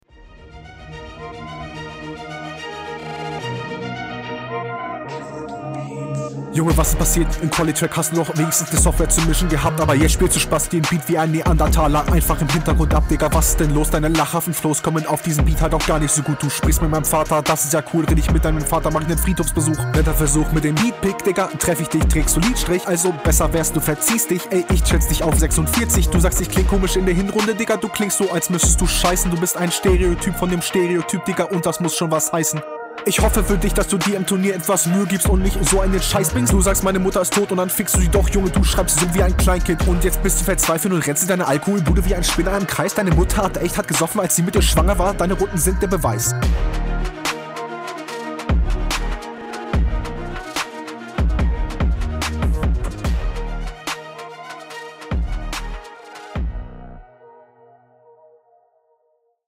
leider sehr oft Off - Beat.
Perfekt, die scharfen S-Laute sind nicht mehr Vorhanden, klingt es jetzt gut? Ja, zwar noch …
Flow an einigen Stellen leicht verhaspelt und unroutiniert, da gefällt mir die HR1 besser, trotzdem …
Flow: coole Flowswitches, aber insgesamt etwas weniger konstant wie in deiner HR. Technik: leicht besser …